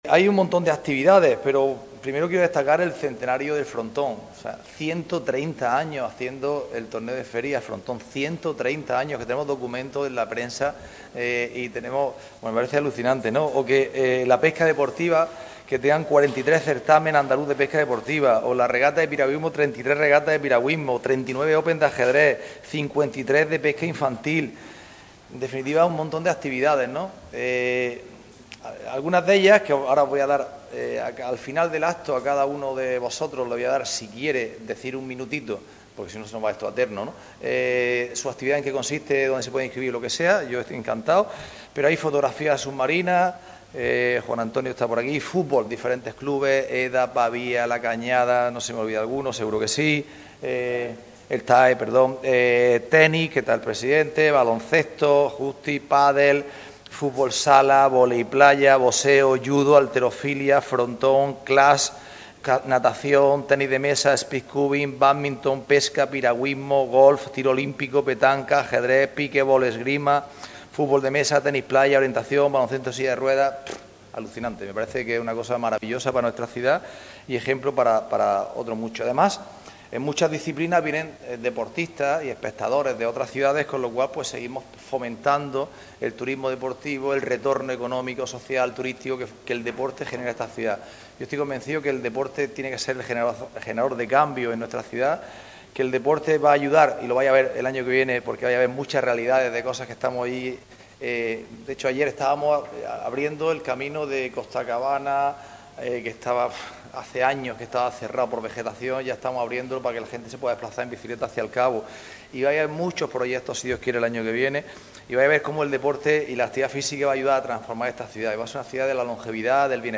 El concejal Antonio Casimiro presenta la programación, arropado por los clubes y federaciones que coordinan cada actividad